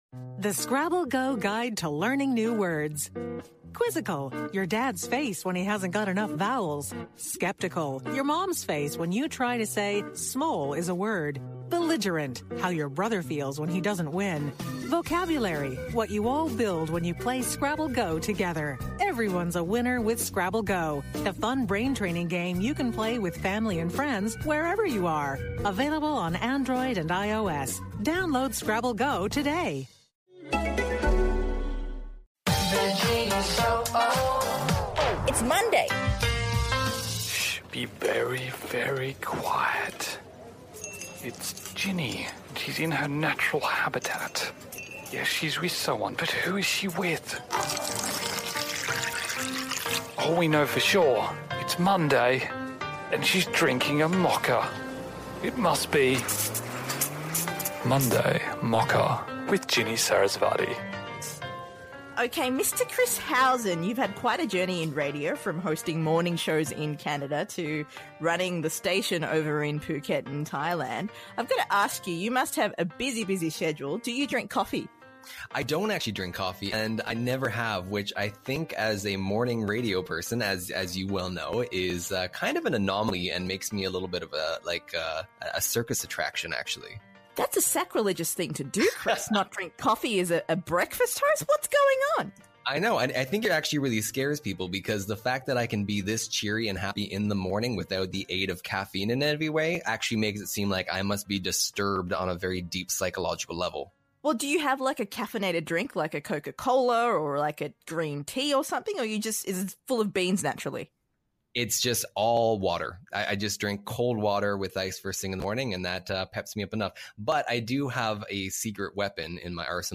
So, this is actually the first ever conversation we've had, live in person.